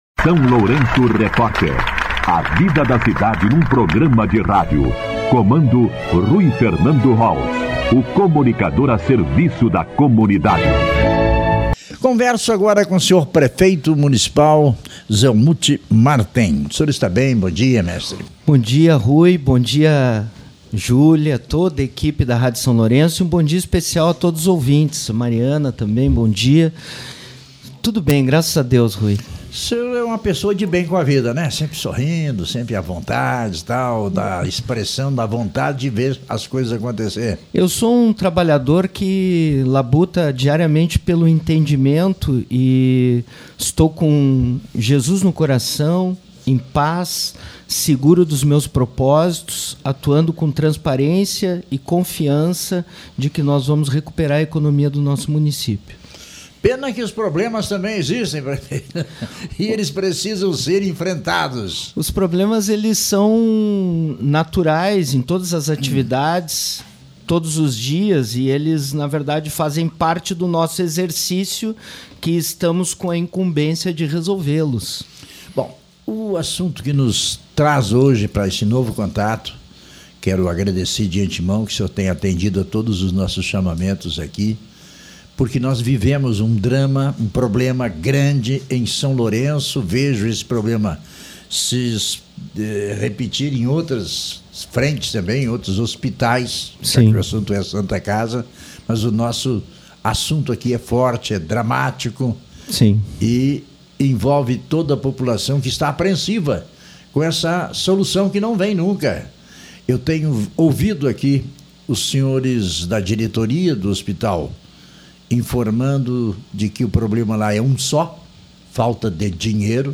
O prefeito de São Lourenço do Sul, Zelmute Marten (PT), concedeu entrevista nesta terça-feira (22) ao SLR RÁDIO, para tratar da grave crise financeira enfrentada pela Santa Casa de Misericórdia do município.
ENTREVISTA-22.7-Prefeito-Zelmute-Marten.mp3